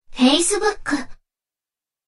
「Facebook」と女の声で言います。
「Facebook」という、かわいい女の子の声。